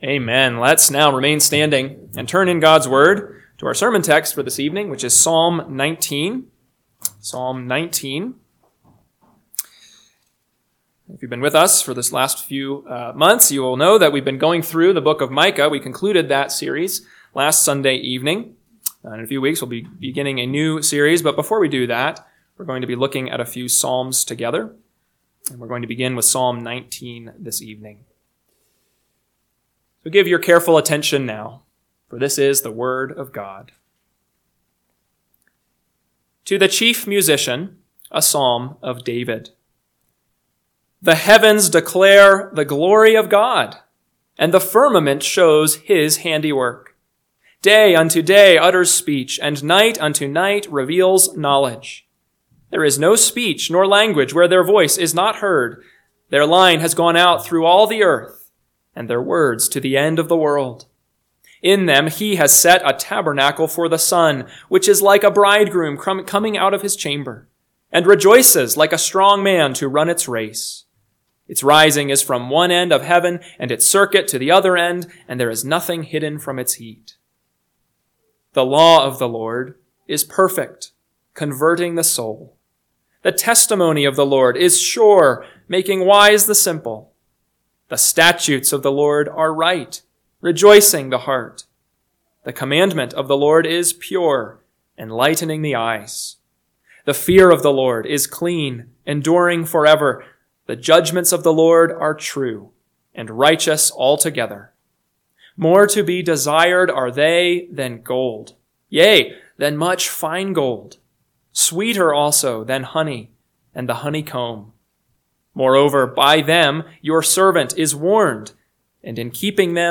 PM Sermon – 1/12/2025 – Psalm 19 – Northwoods Sermons